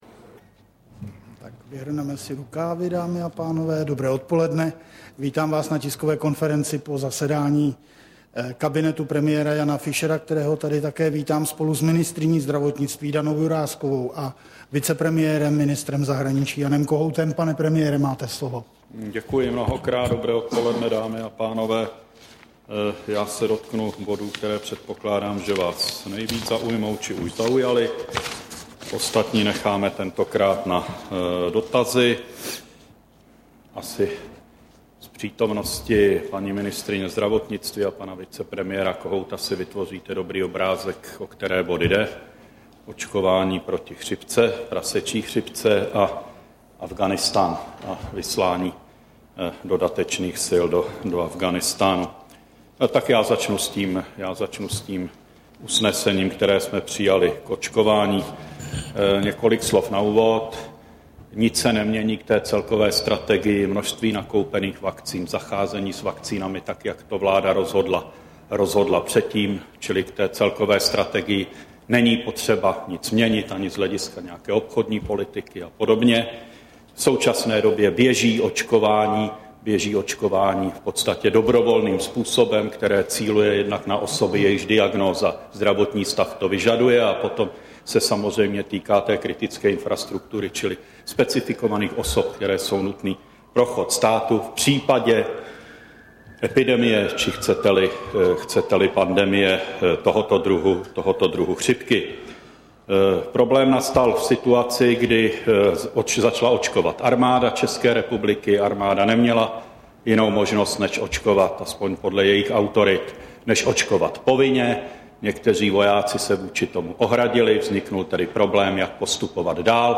Tisková konference po zasedání vlády, 1.2.2010